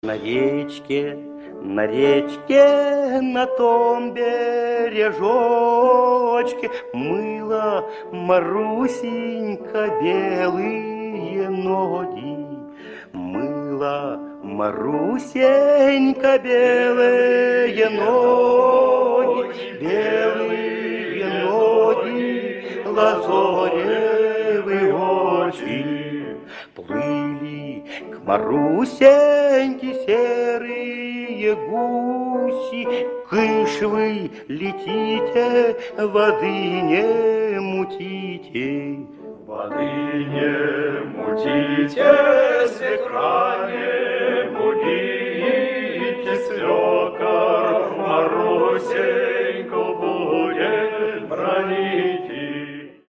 Народная песня